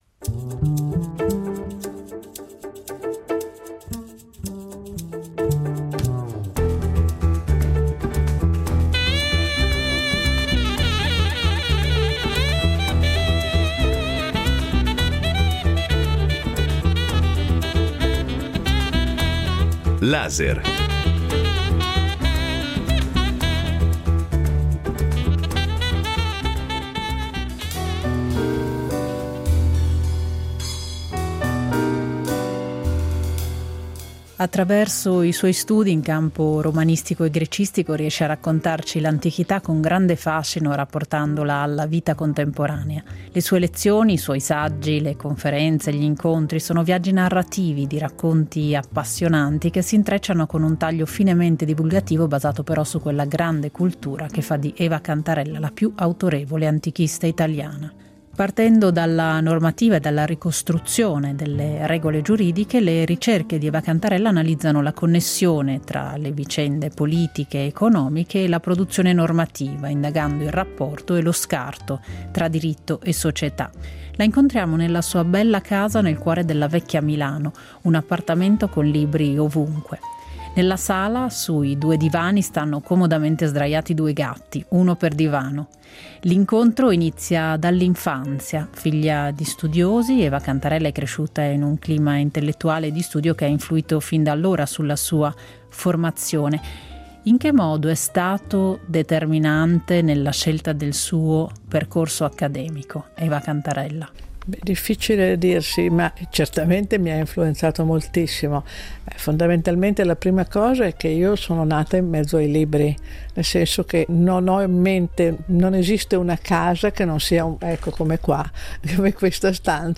Abbiamo incontrato la studiosa a Milano per questo doppio Laser.